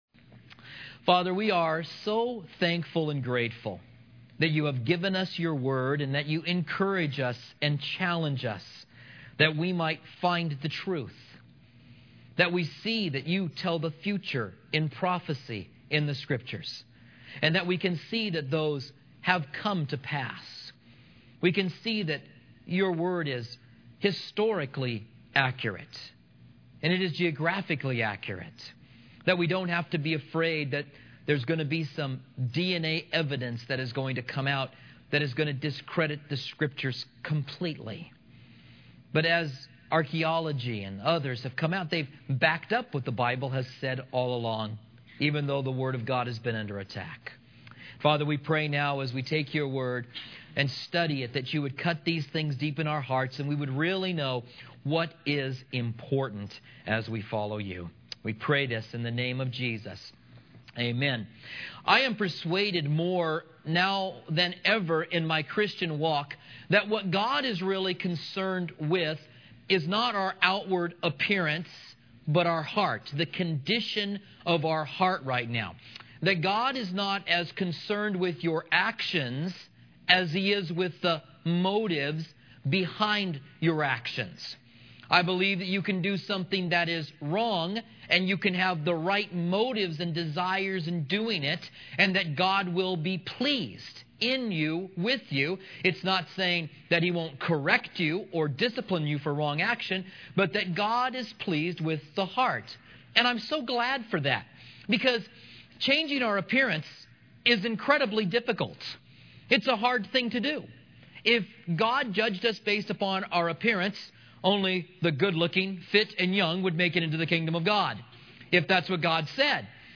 Commentary on 1 Kings